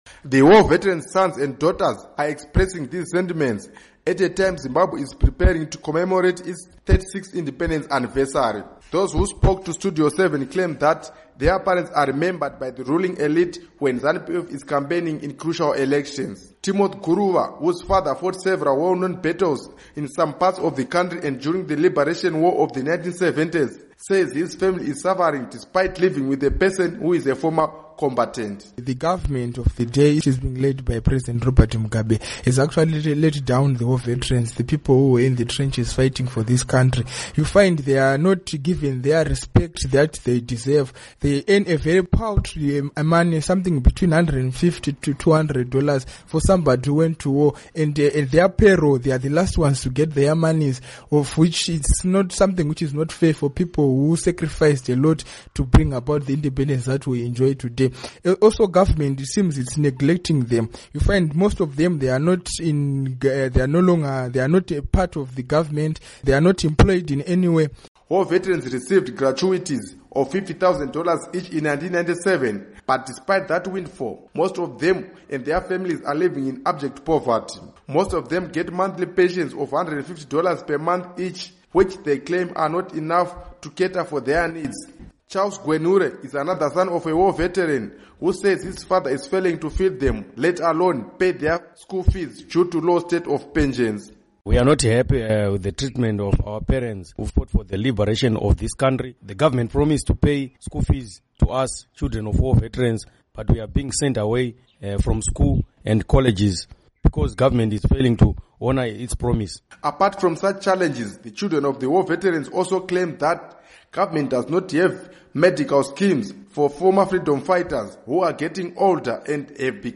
Report on War Veterans children